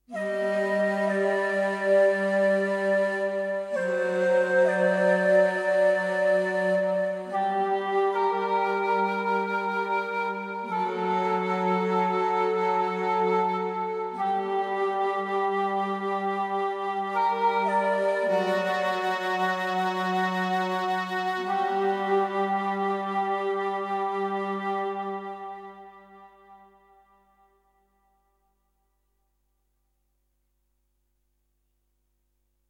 Música ambiental del cuento: El príncipe serpiente
ambiente
sintonía